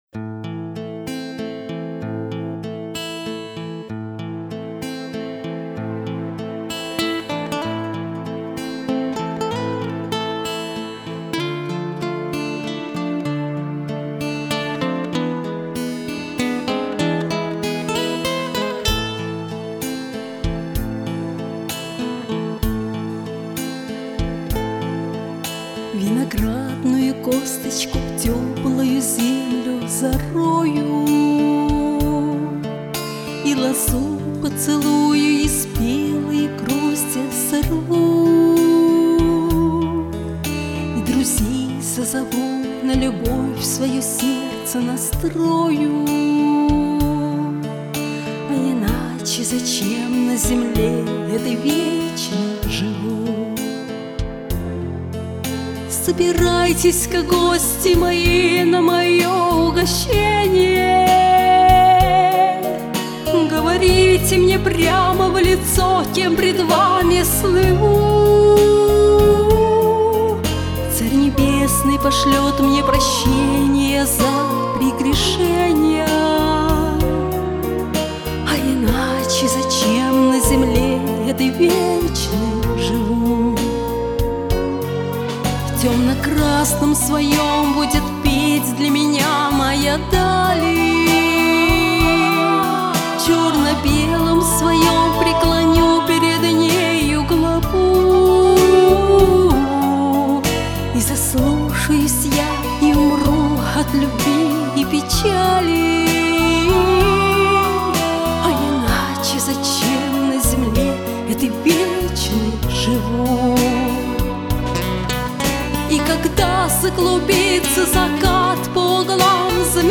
Исключительный по звучанию минус.